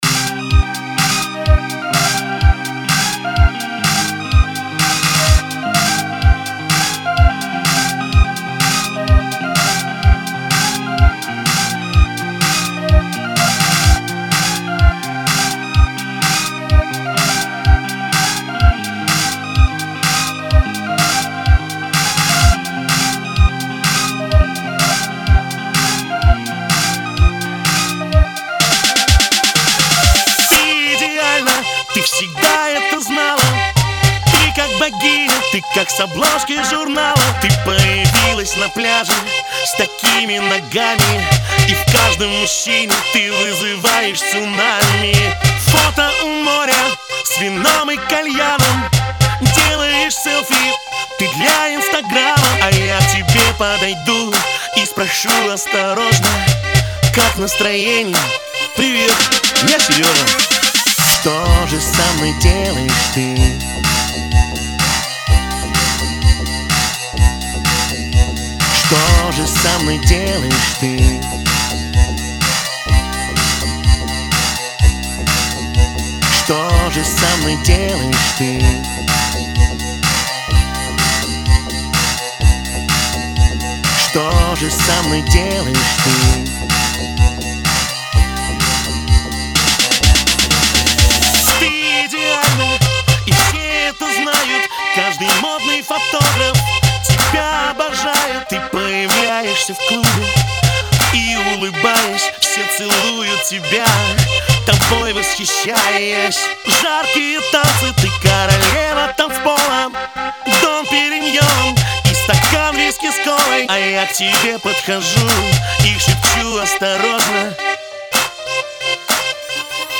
У меня трек имеет не такую глубину как надо.